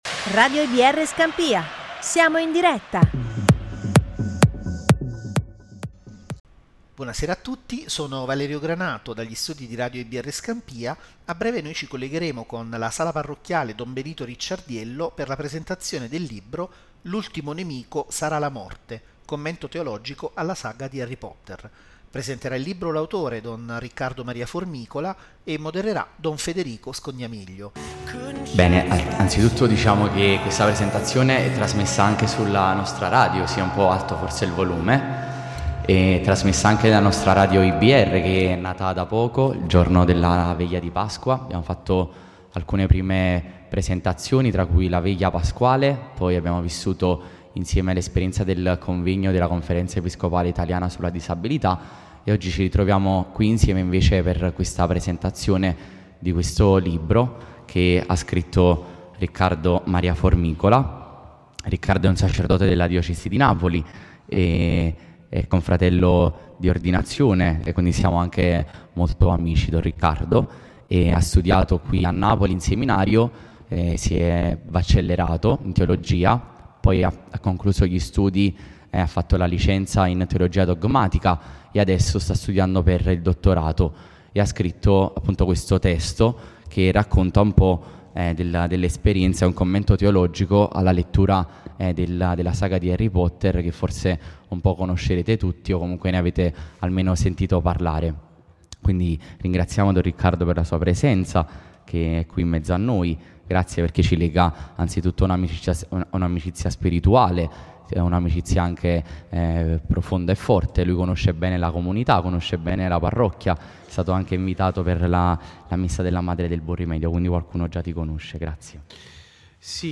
Il 30 aprile 2024, presso la sala parrocchiale Don Benito Ricciardiello di Scampia